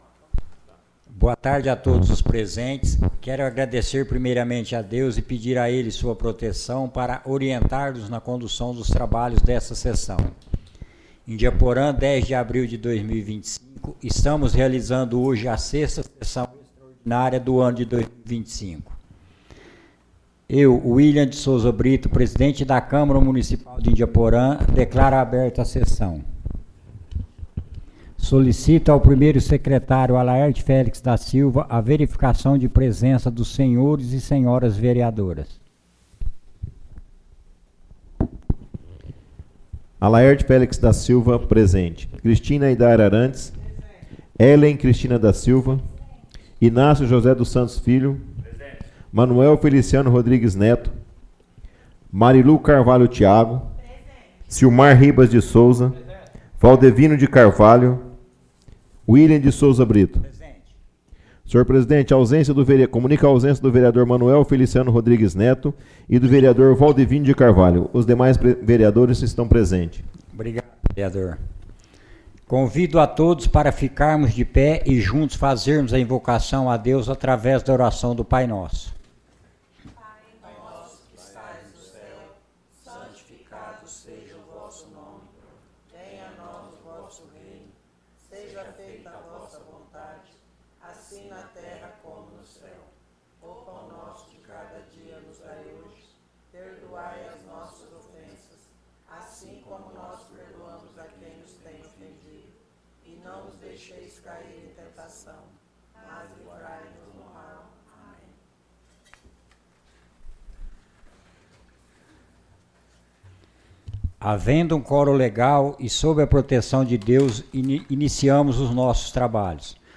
Resumo (6ª Extraordinária da 71ª Sessão Legislativa da 18ª Legislatura)
Tipo de Sessão: Extraordinária